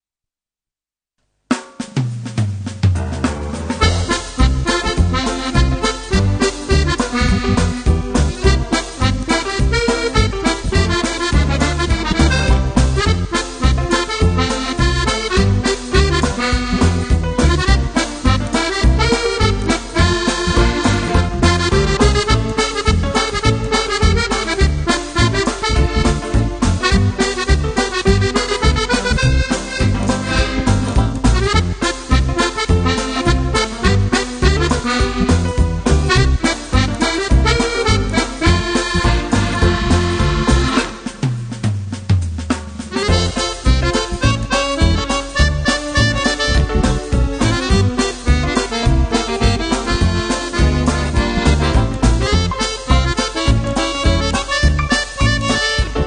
Titres enchain?s pour danser